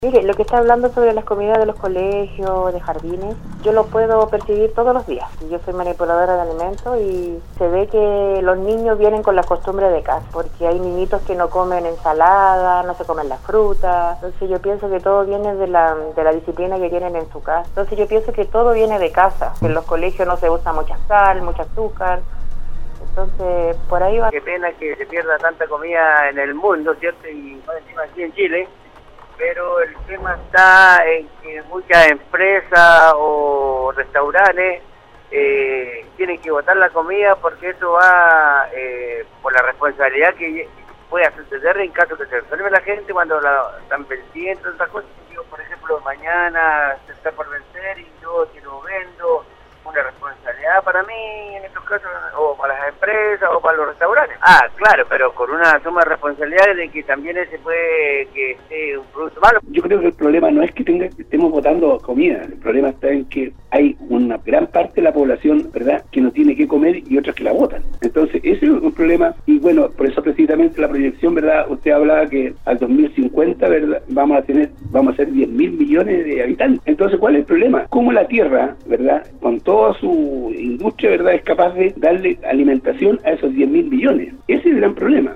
La mañana de este miércoles, los auditores de Nostálgica participaron en el foro del programa Al Día, donde se refirieron a la alerta que realizó el Ministro de Agricultura (s), Alfonso Vargas, sobre que en Chile se estén desperdiciando al menos 3700 millones de kilos en alimentos y el gasto que esto implica.